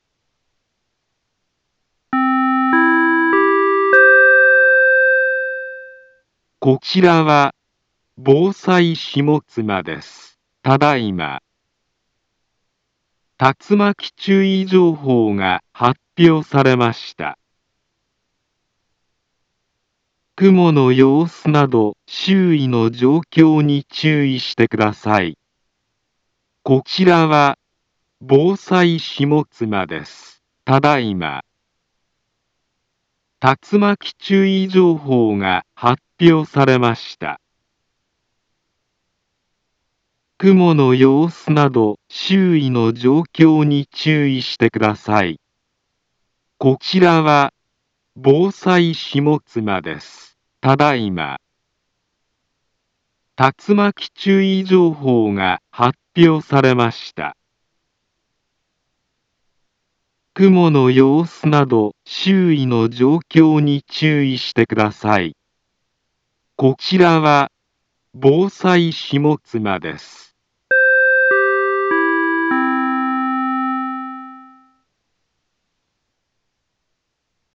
Back Home Ｊアラート情報 音声放送 再生 災害情報 カテゴリ：J-ALERT 登録日時：2024-11-27 01:24:31 インフォメーション：茨城県南部は、竜巻などの激しい突風が発生しやすい気象状況になっています。